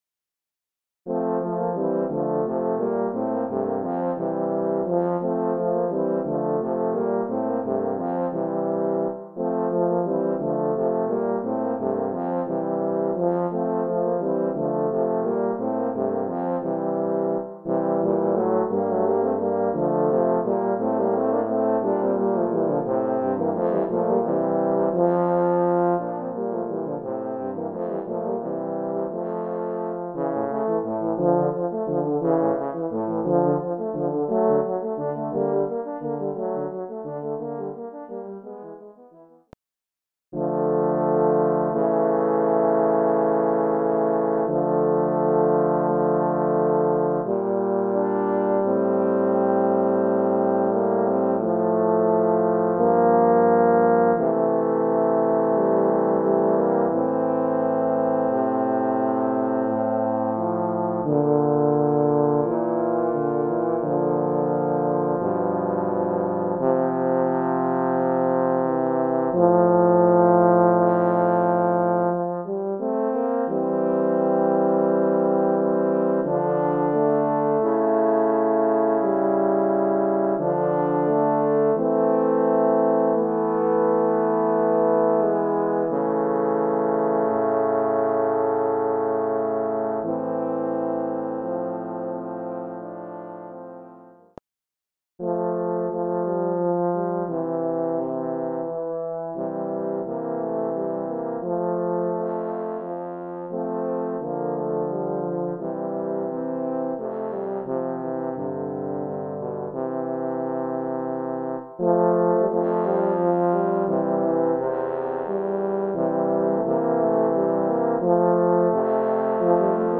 Voicing: Horn Trio